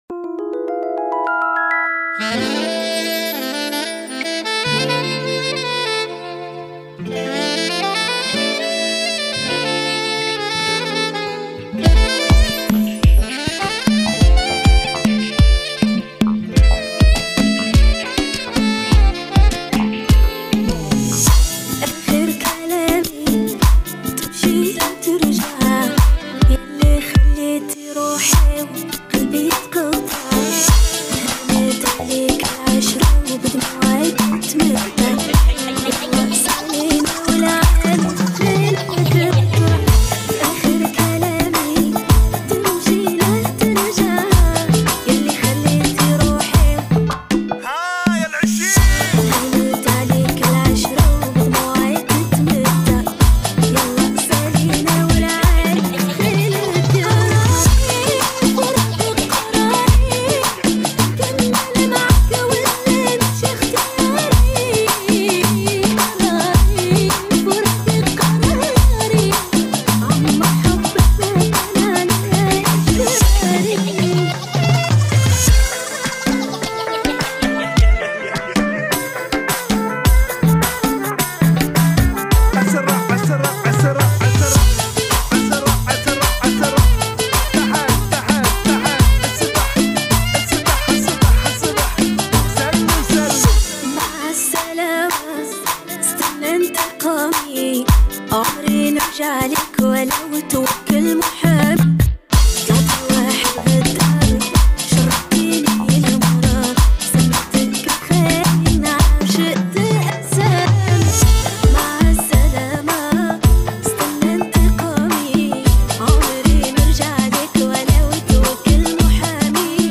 ريمكس